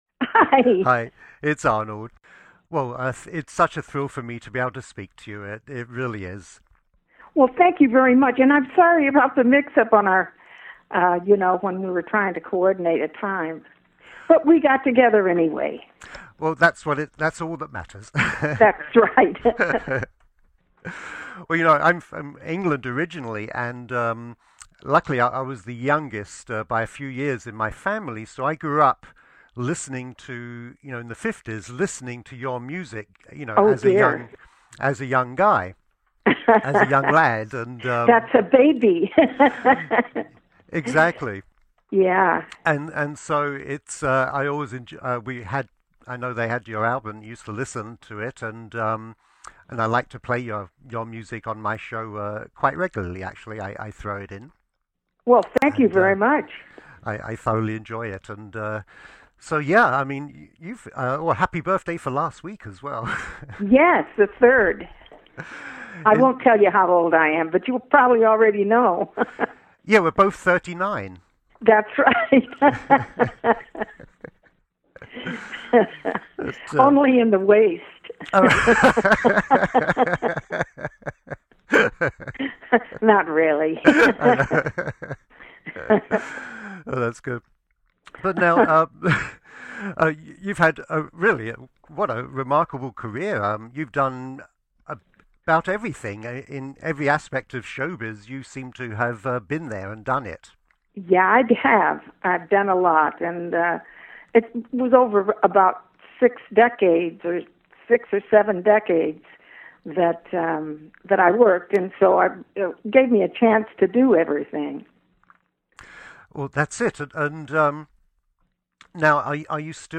Musician Interviews